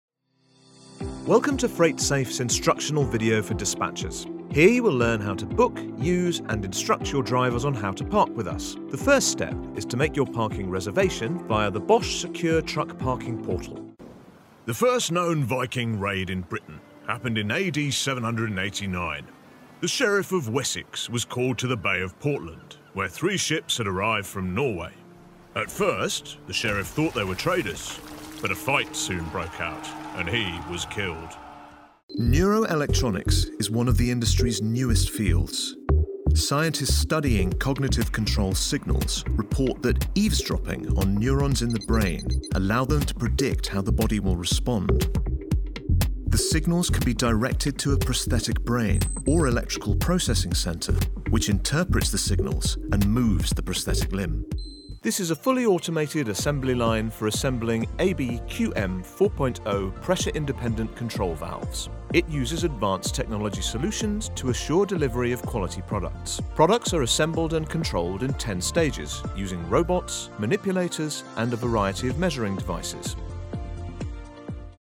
Male
Warm, convincing, trustworthy voice and genuine RP (Received Pronunciation) accent
E-Learning
Instructional Content